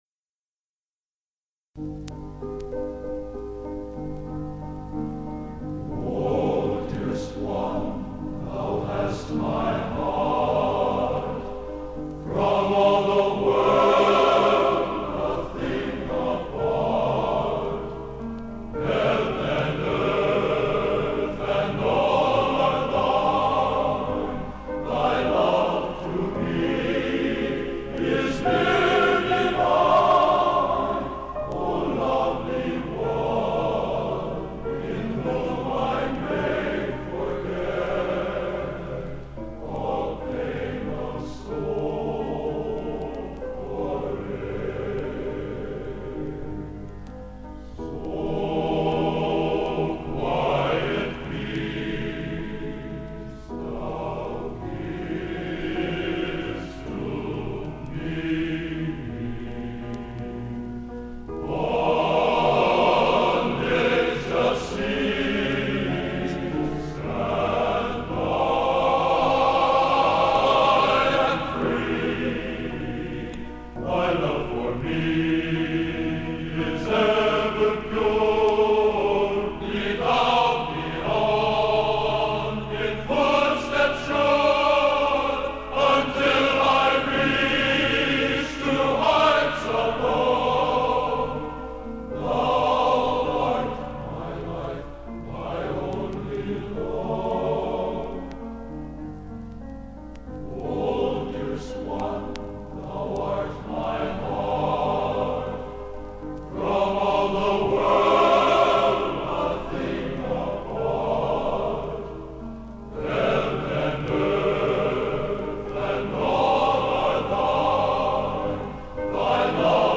Location: West Lafayette, Indiana
Genre: | Type: Studio Recording